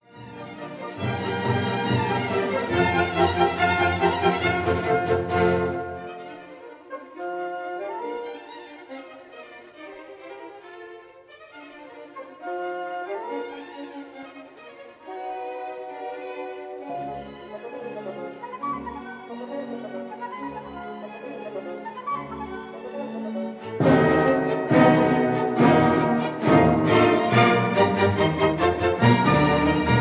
stereo recording made in Hammersmith Town Hall, London